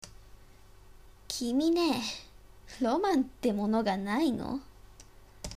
...@"声･台詞の属性...@"